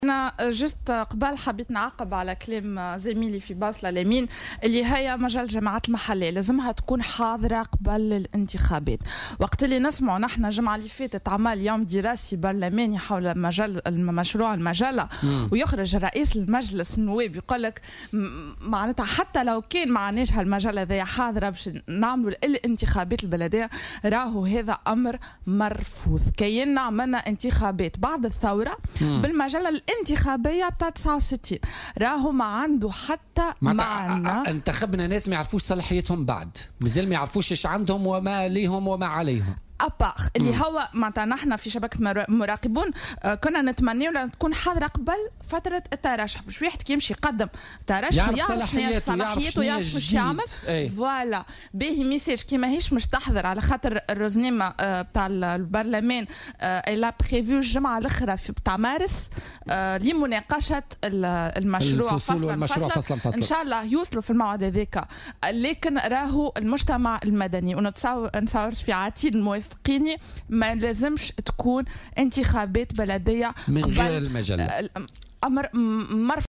وأوضحت في مداخلة لها اليوم في برنامج "بوليتيكا" أن شبكة مراقبون دعت إلى المصادقة على المجلة قبل فتح باب الترشح للانتخابات، وذلك حتى يتعرّف كل مترشح على صلاحياته، وفق تعبيرها. وأكدت أن إجراء الانتخابات دون مجلة الجماعات المحلية في صيغتها الجديدة أمر غير ممكن و ليس له أي معنى.